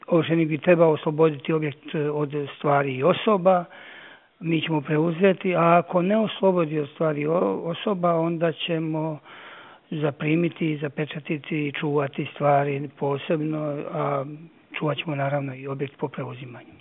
Na taj je datum u 10 sati ujutro Općinski sud u Šibeniku zakazao ovrhu otokom Smokvica Vela, potvrdio je za Media servis ministar državne imovine Goran Marić.